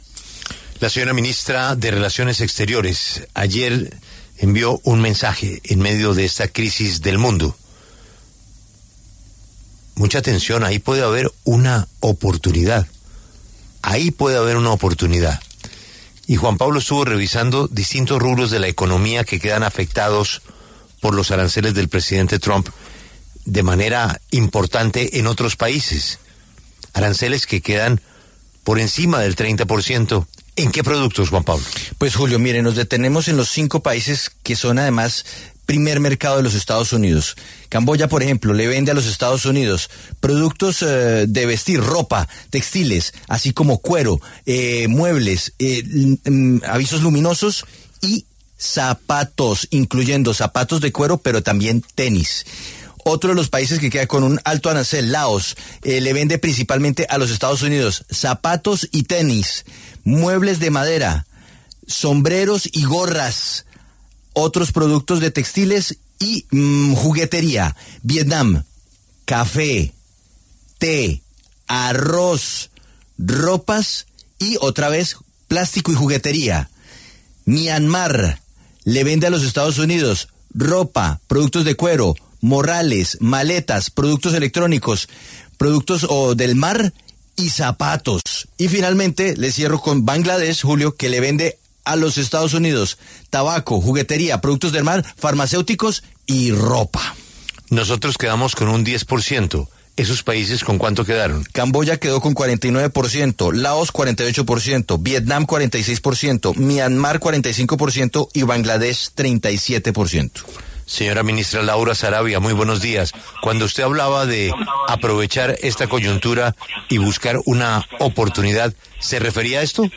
En entrevista con La W, la canciller, Laura Sarabia, afirmó que la diversificación de mercados para Colombia no significa el reemplazo de Estados Unidos.